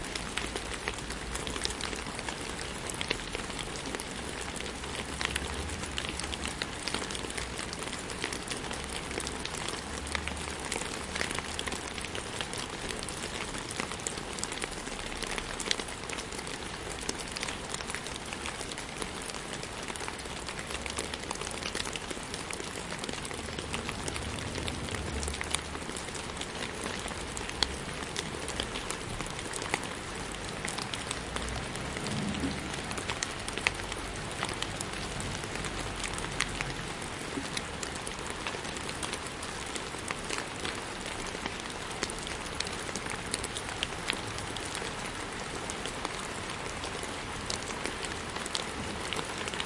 8分钟的雨 " 雨在草地上pt
描述：在暴雨期间，雨滴下滴在草，关闭。
Tag: 环境 ATMO 暴雨 气象 现场录音